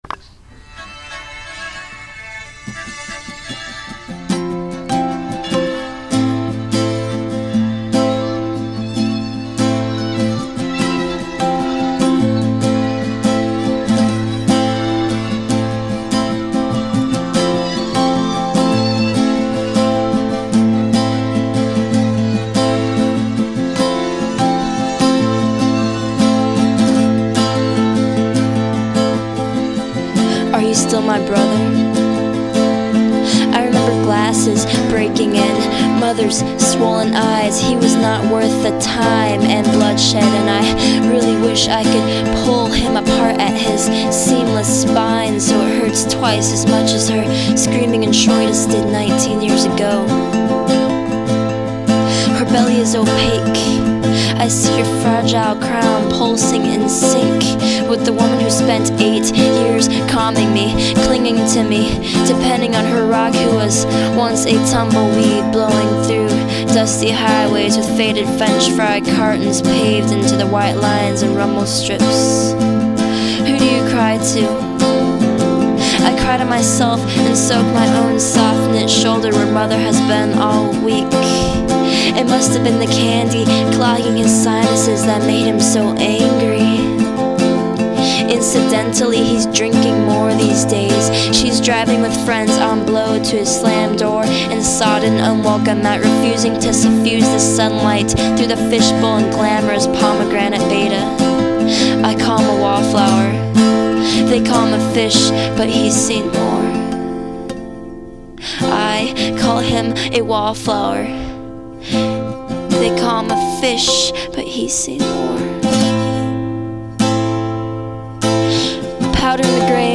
Spoken Word 3.MP3